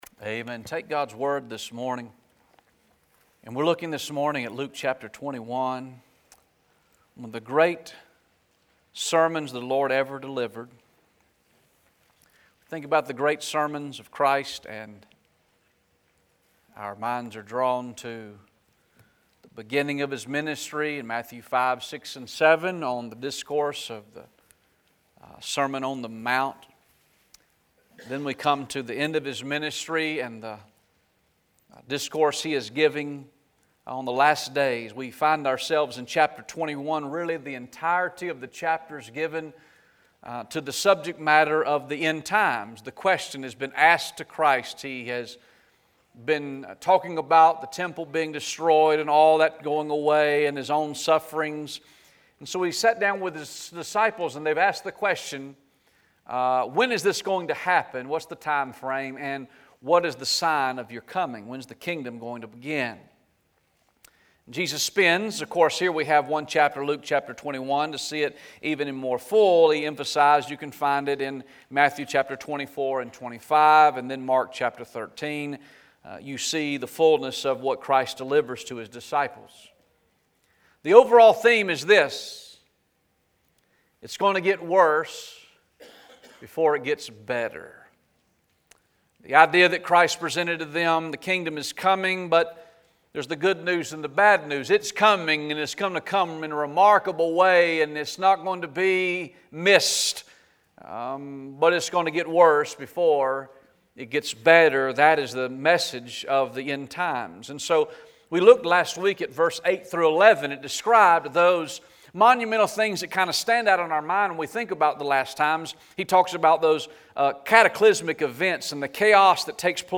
Sunday, November 24th, 2019 am service